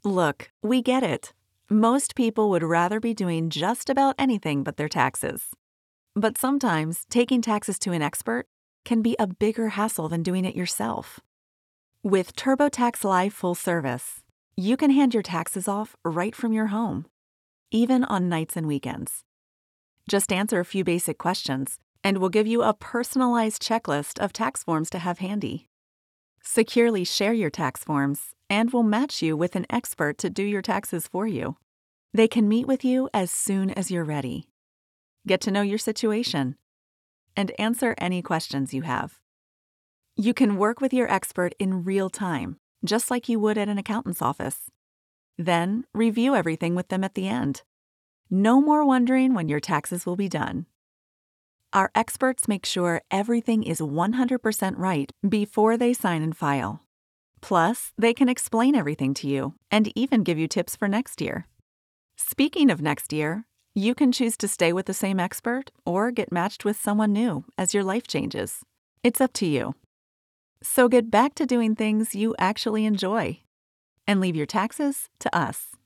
Conversational Explainer
PROFESSIONAL HOME STUDIO
Vocal booth
TurboTax-Live-real-person-conversational.mp3